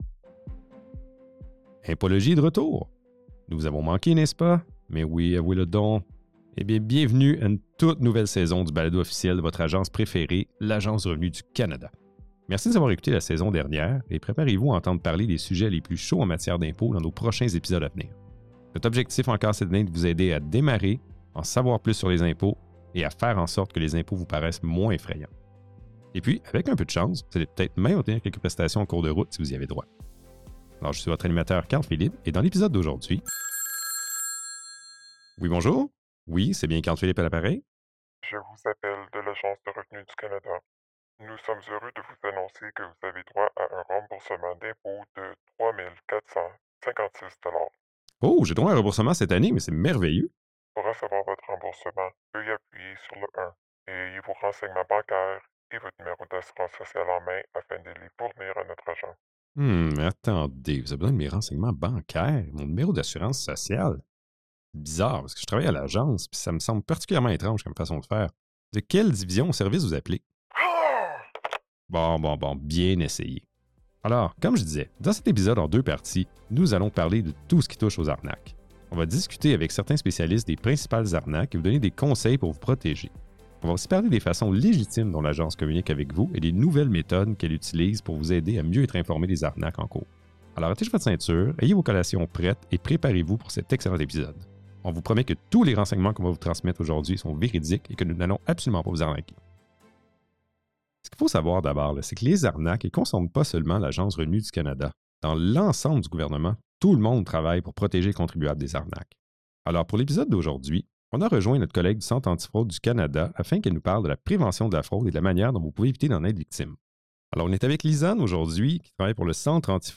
Dans cet épisode, le Centre antifraude du Canada (CAFC) donne des conseils qui vous aideront à vous protéger contre les fraudeurs. Dans cet épisode, une spécialiste du CAFC se joint à nous pour parler des diverses fraudes commises au gouvernement du Canada. Elle nous présente les signes avant-coureurs à surveiller dans les messages frauduleux, les fraudes les plus courantes et ce qu’il faut faire si vous ou un membre de votre famille avez été victime d’une fraude.